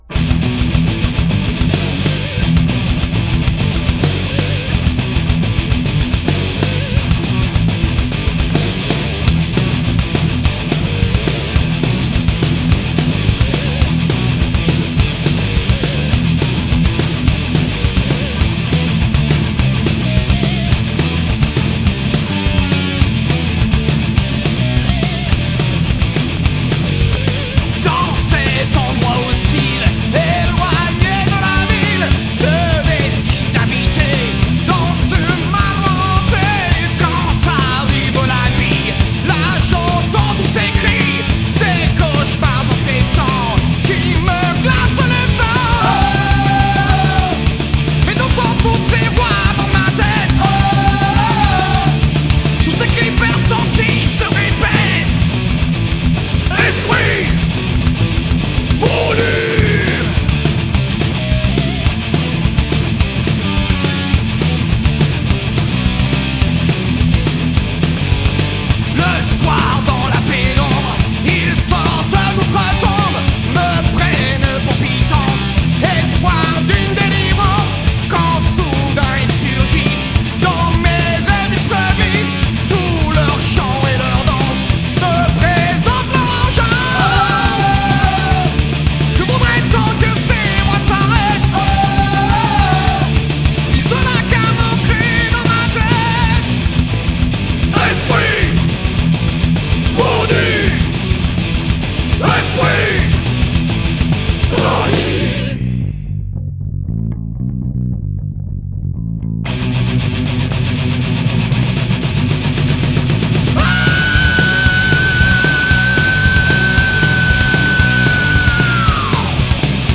heavy metal France